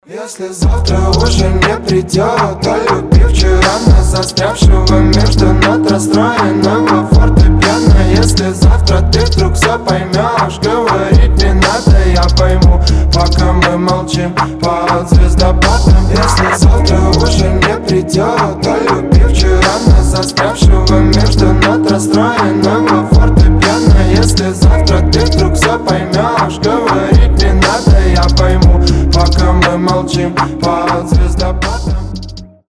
мужской голос
ритмичные
атмосферные
спокойные